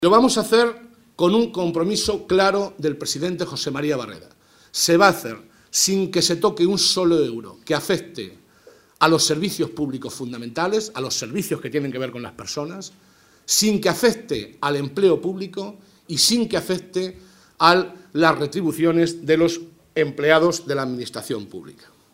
El portavoz del Grupo Parlamentario Socialista, José Molina, ha destacado, en una rueda de prensa en Ciudad Real, que el Gobierno de Castilla-La Mancha acometerá un “esfuerzo importante” a través del Plan de Consolidación de las Cuentas Públicas, aprobado esta misma mañana por el Consejo de Gobierno.